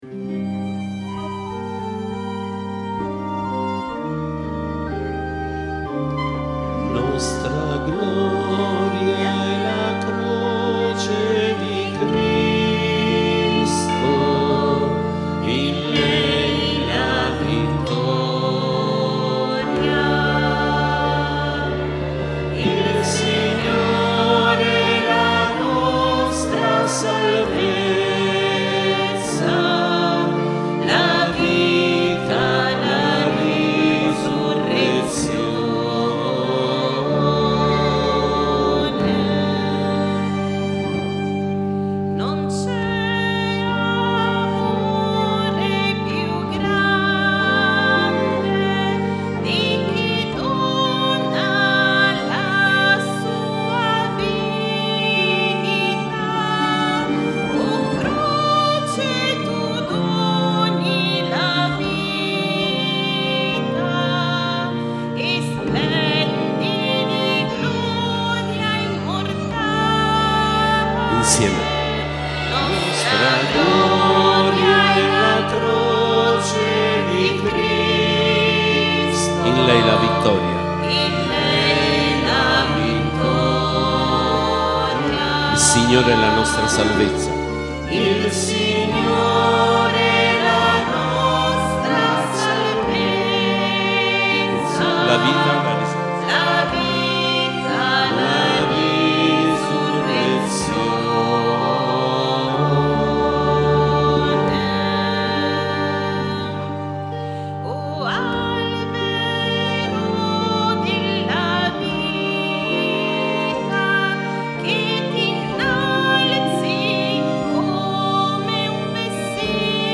Canti Venerdì Santo 2022